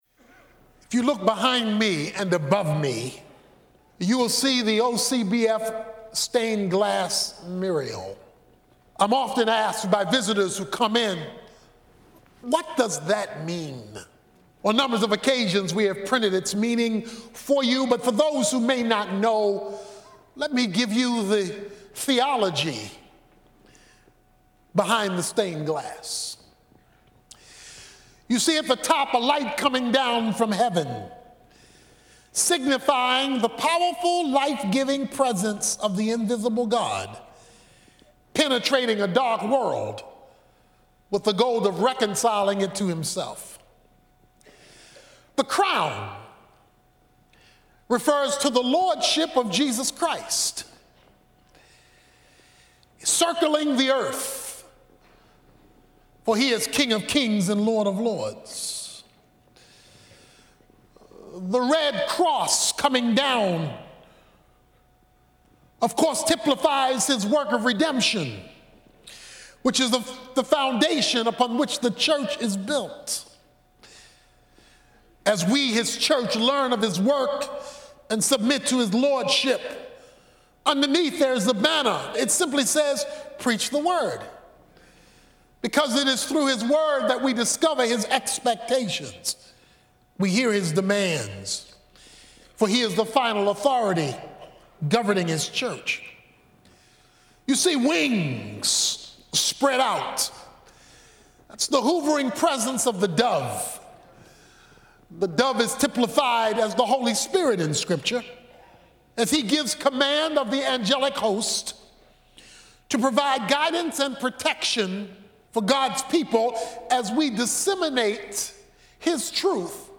Download a free MP3 of the powerful sermon, Water in God's House below.